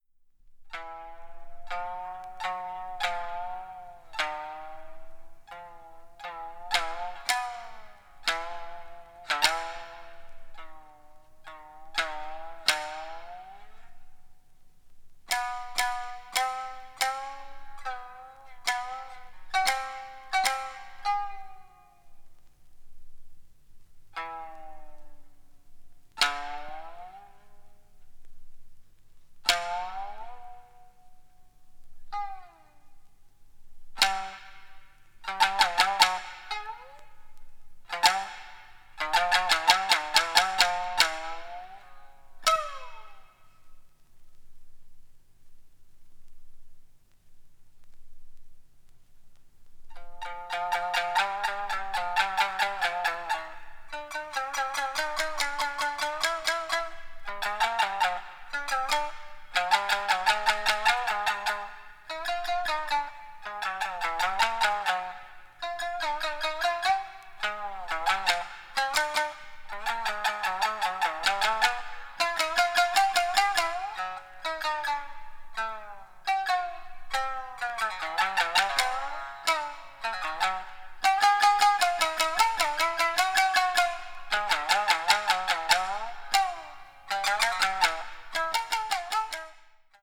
三絃